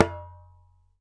ASHIKO 4 05L.wav